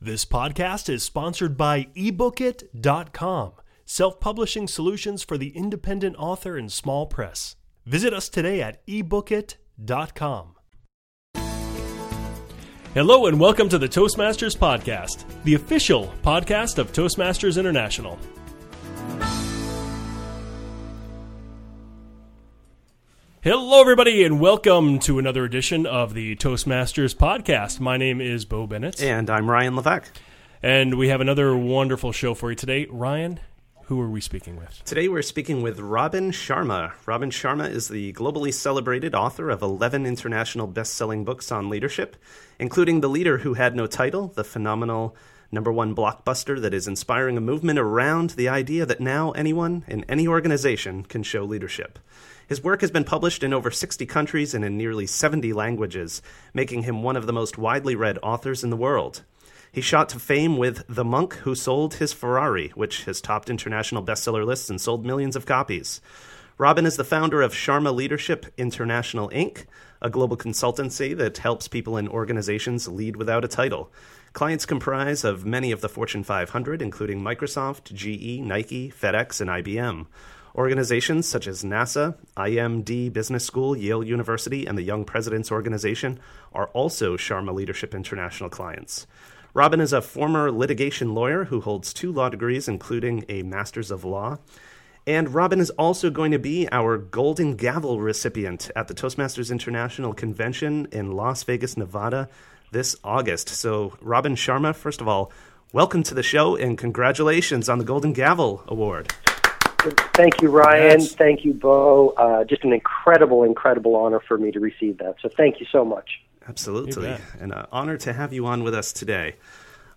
Robin joins the show to share insights into a new leadership model that he calls, “Leadership 2.0.”